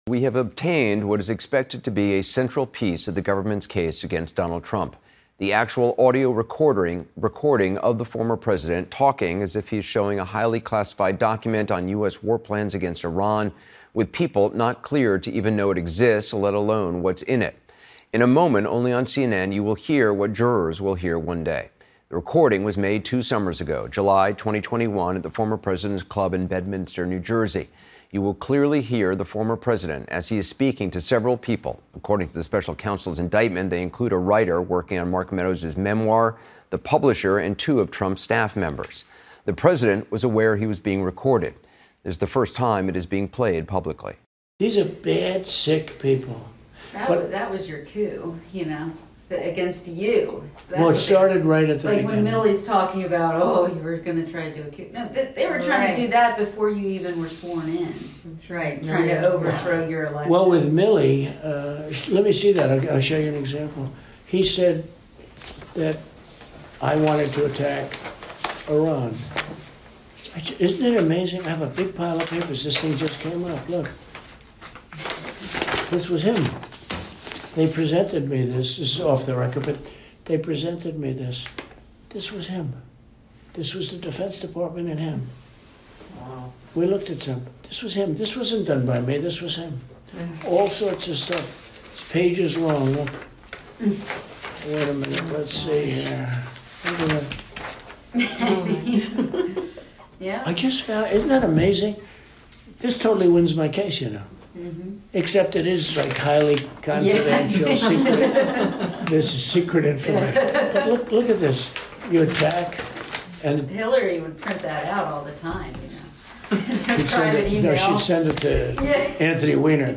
CNN has exclusively obtained the sound effects free download By cnn 1129 Downloads 28 months ago 164 seconds cnn Sound Effects About CNN has exclusively obtained the Mp3 Sound Effect CNN has exclusively obtained the audio recording of the 2021 meeting in Bedminster, New Jersey, where former President Donald Trump discusses holding secret documents he did not declassify.